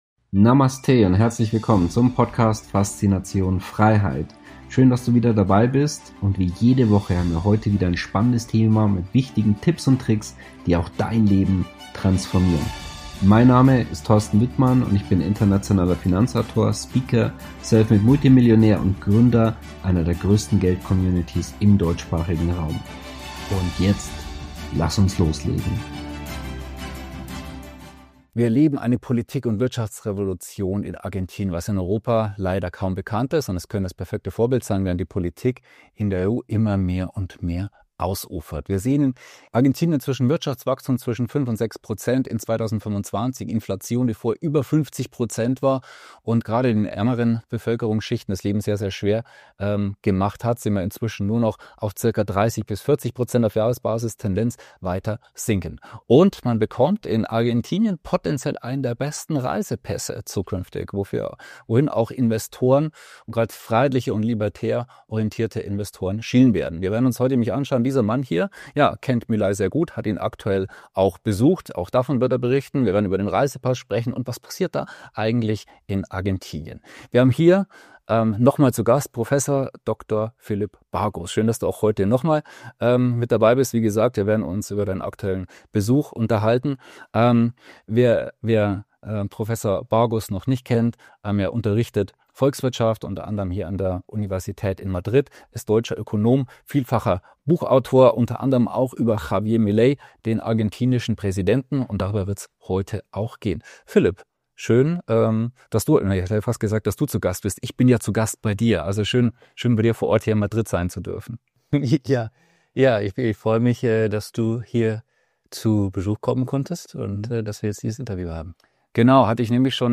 In unserem Interview diskutieren wir: Was passiert wirklich in Argentinien?